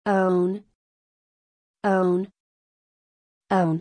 発音記号：own
/əʊn/